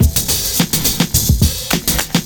106CYMB05.wav